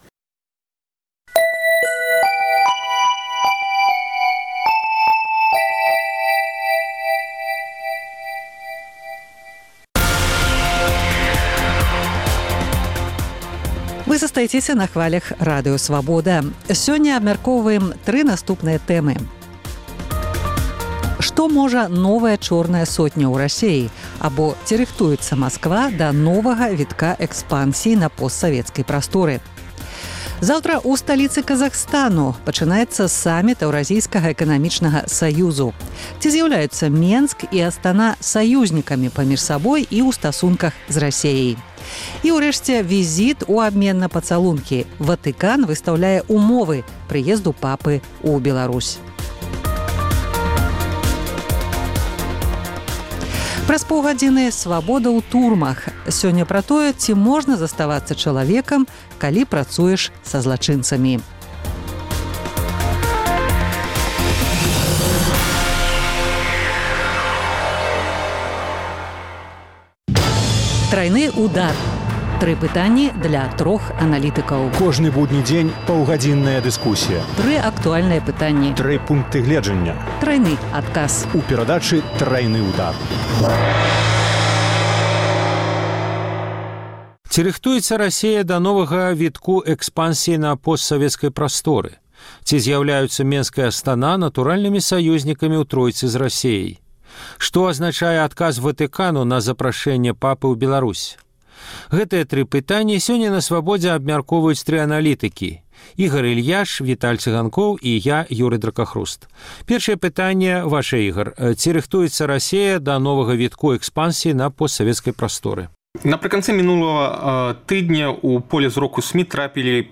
Тры пытаньні да трох аналітыкаў: Ці рыхтуецца Расея да новага вітка экспансіі на постсавецкай прасторы? . Ці зьяўляюцца Менск і Астана натуральнымі саюзьнікамі паміж сабой і ў стасунках з Расеяй? Што азначае адказ Ватыкану на запрашэньне папы ў Беларусь?